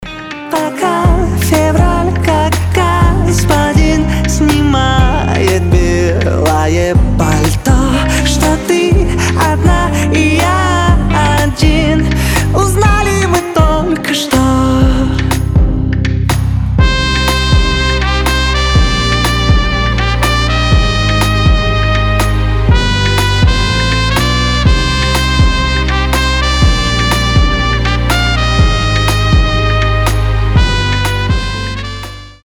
• Качество: 320, Stereo
поп
Cover
инструментальные
Саксофон
труба